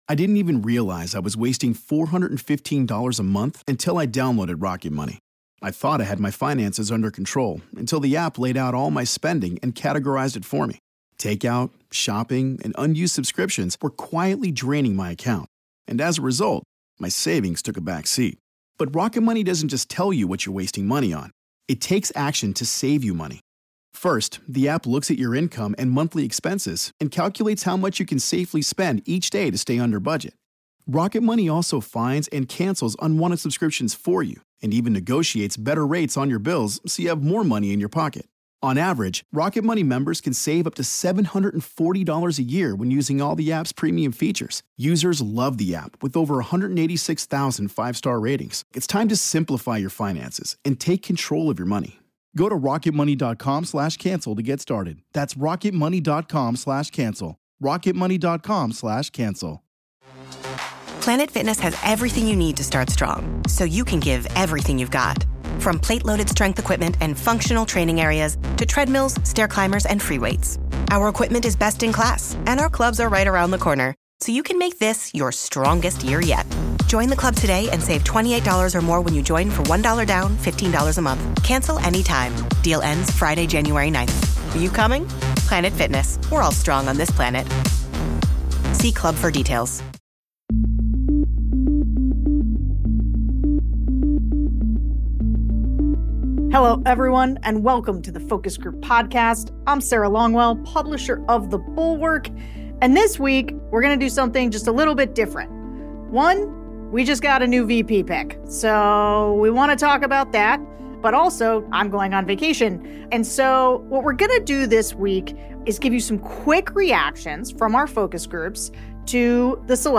Listen to how swing voters from around the country AND Minnesota voters who know Walz best are thinking about him and the presidential race more broadly.